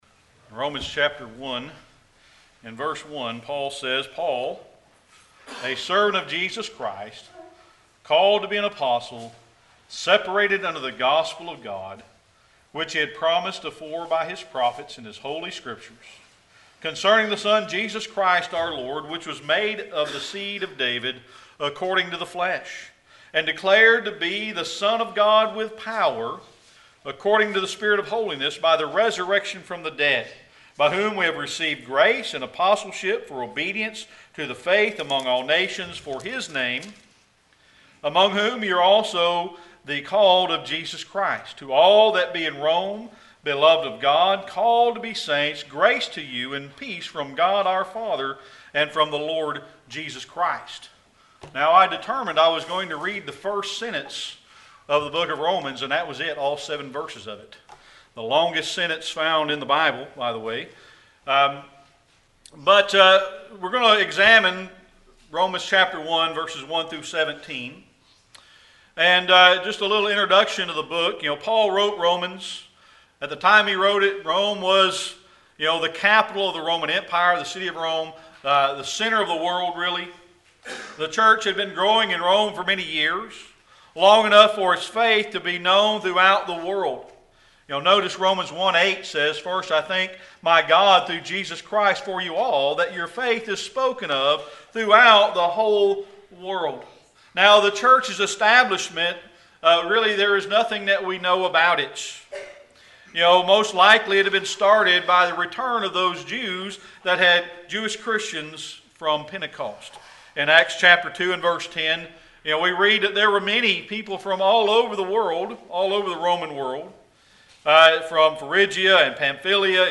Series: Sermon Archives
Romans 1:1 -17 Service Type: Sunday Evening Worship Paul wrote Romans when Rome was the capital of the Roman empire.